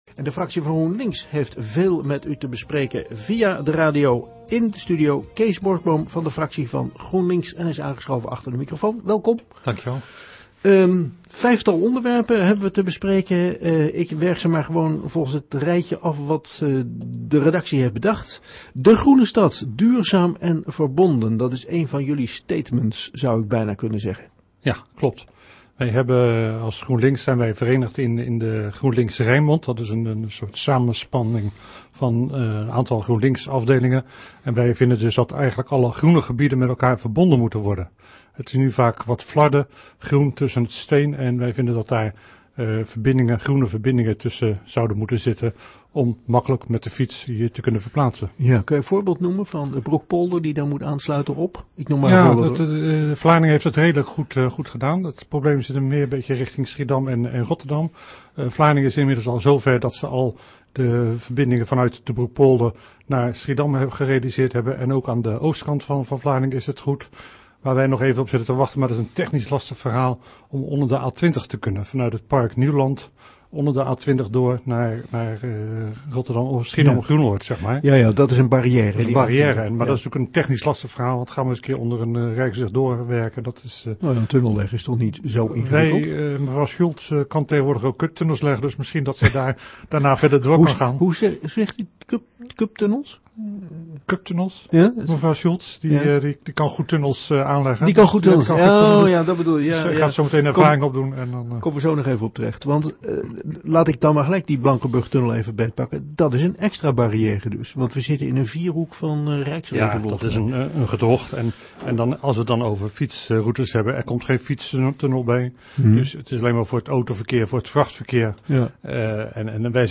GroenLinks fractievoorzitter geïnterviewd bij Omroep Vlaardingen
Afgelopen week Kees Borsboom geïnterviewd door onze plaatselijke omroep over vijf Vlaardingse onderwerpen waar GroenLinks zich hard voor maakt.